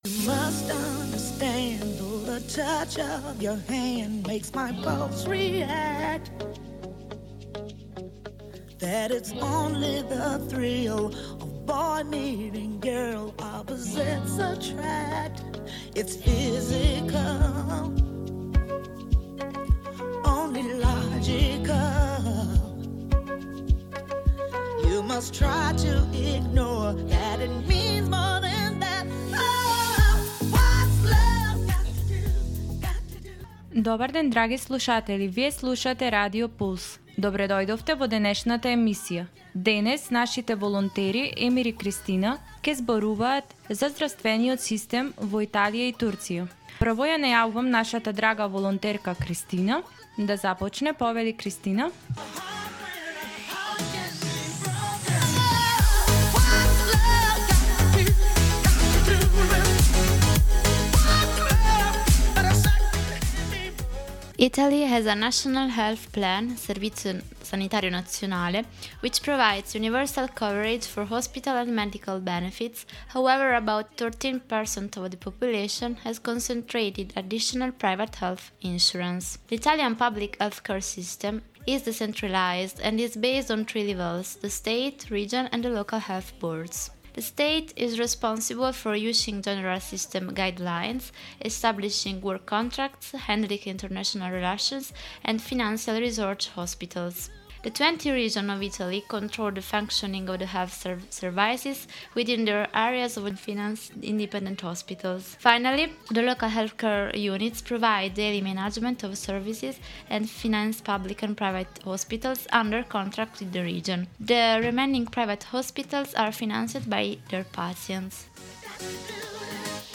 It’s a mix of facts, personal experiences, and laughs — all wrapped up in a cross-cultural conversation that will leave you both informed and entertained.